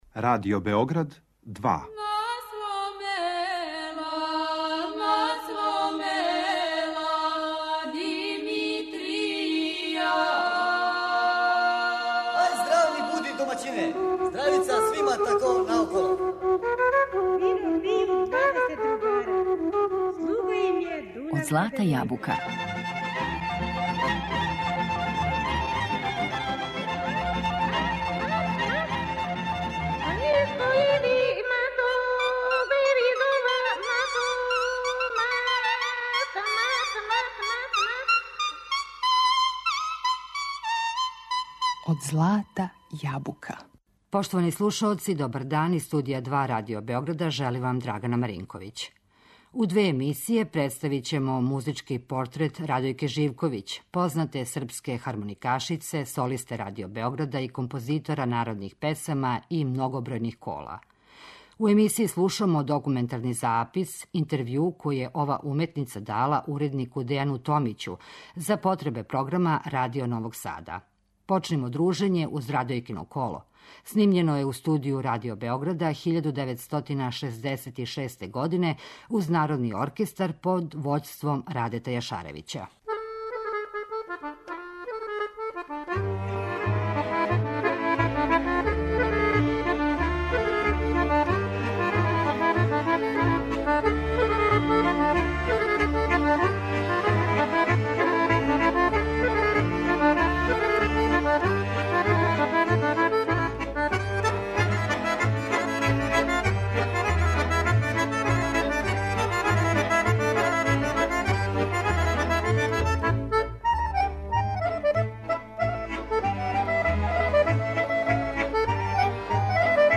Српском народу подарила је прелепа кола и песме. Њен начин стварања и музичко мишљење толико је блиско традиционалној музици да се скоро од ње и не разликује.